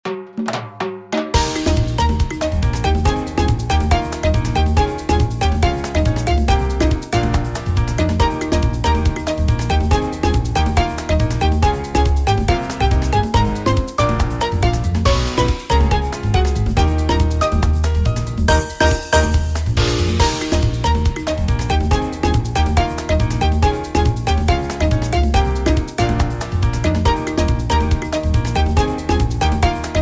Edited Shortened and fadeout